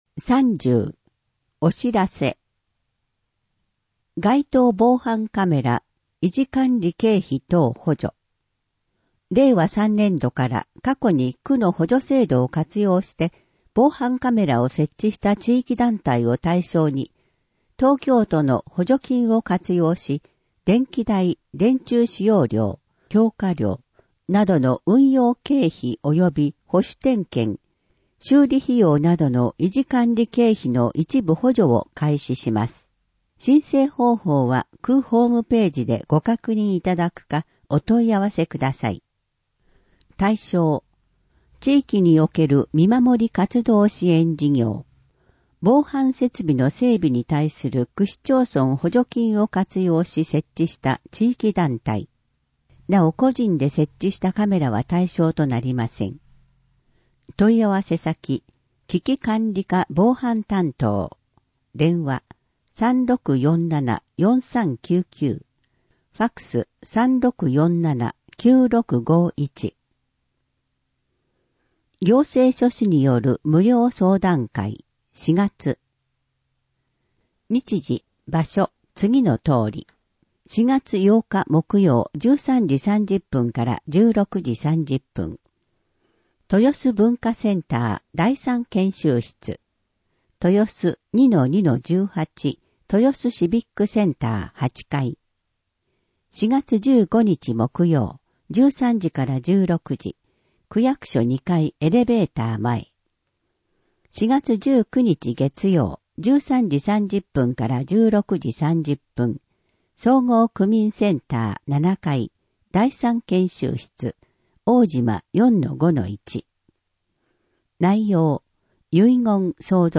声の広報 令和3年4月1日号（1-10面）